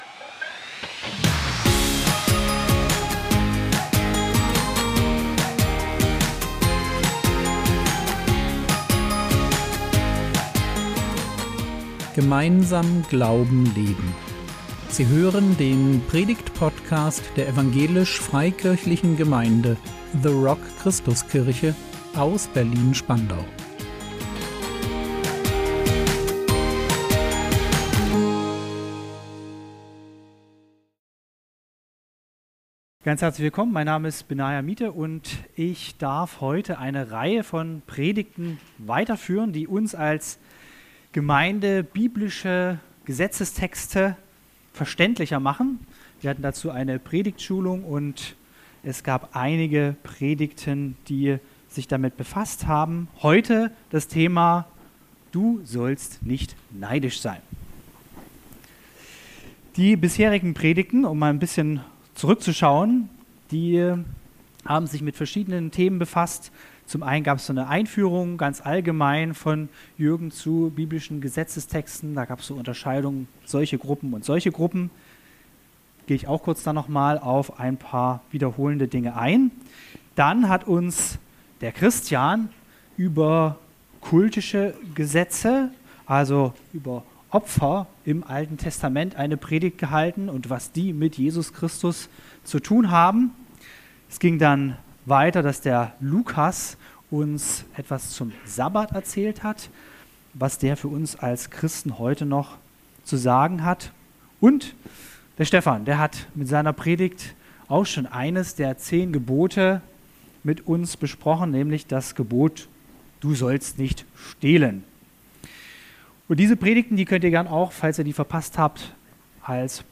Du sollst nicht neidisch sein | 04.05.2025 ~ Predigt Podcast der EFG The Rock Christuskirche Berlin Podcast